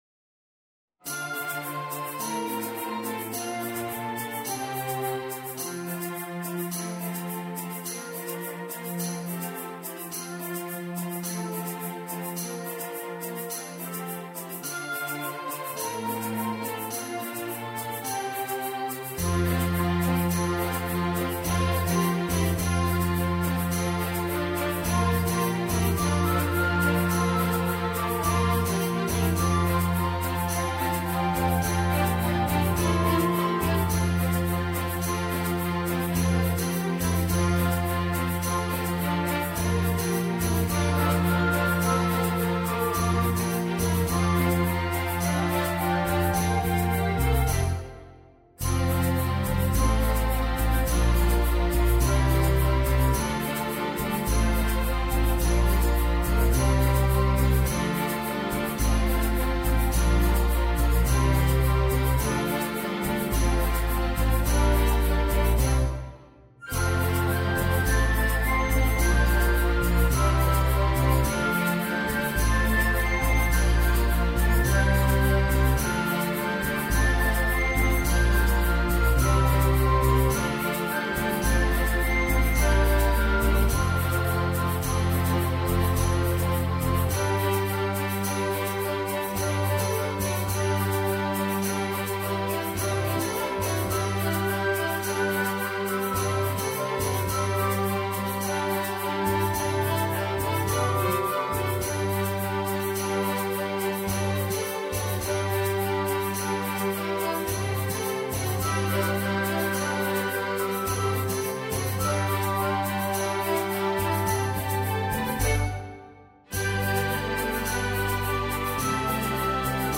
Genre: Musical.